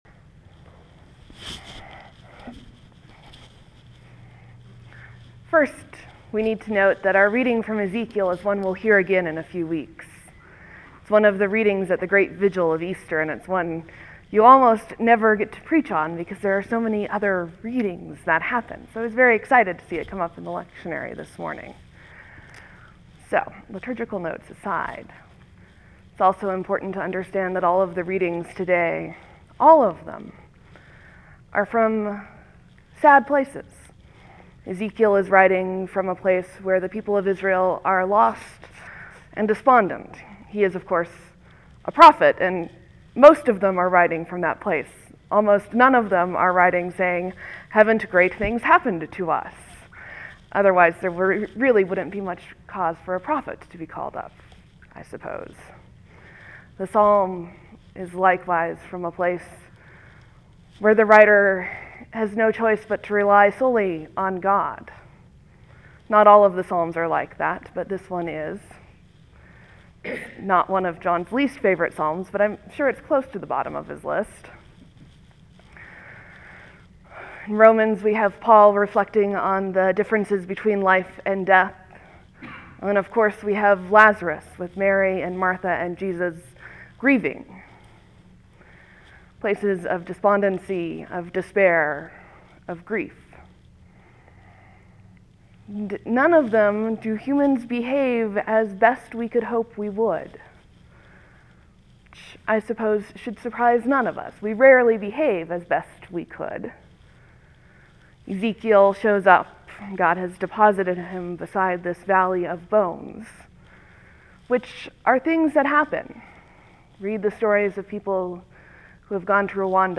Lent, Sermon, , Leave a comment
(There will be a few moments of silence before the sermon begins. Thank you for your patience.)